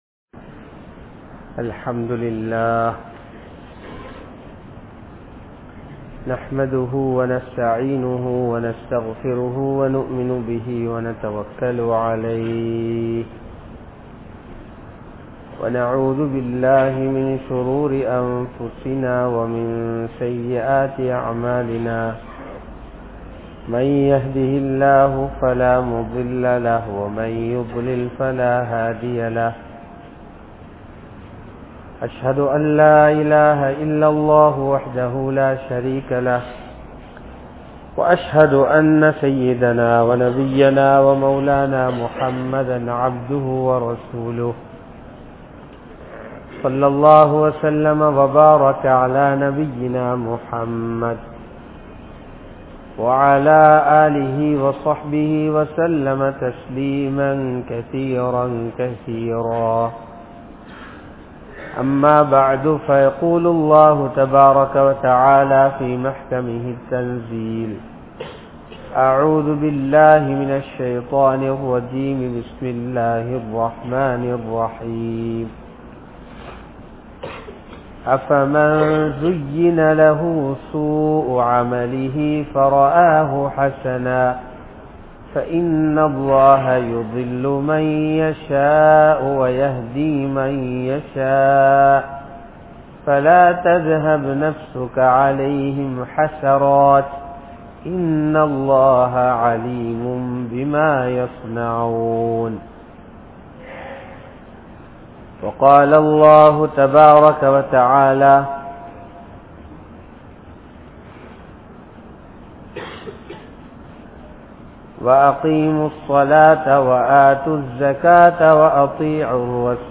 Vaalkaiyai Alikkum Paavangal (வாழ்க்கையை அழிக்கும் பாவங்கள்) | Audio Bayans | All Ceylon Muslim Youth Community | Addalaichenai
Asna Jumua Masjith